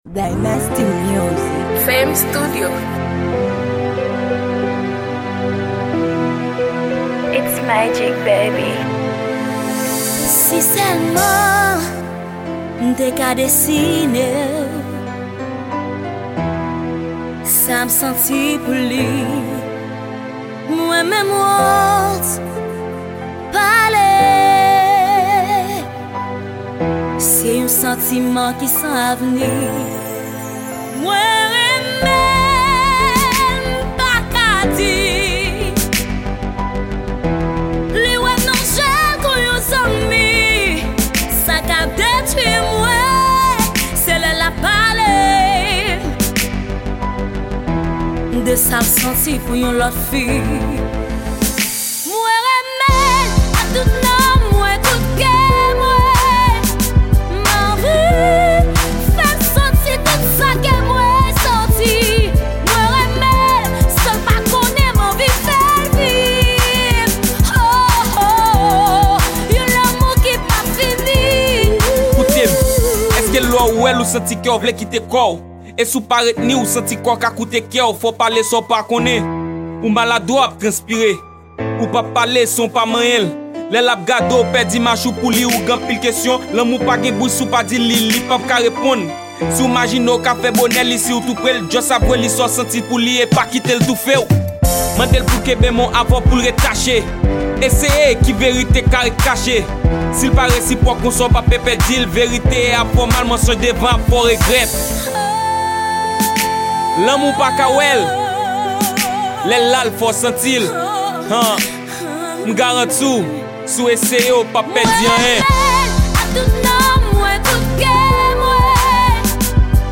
Genre: classic rap.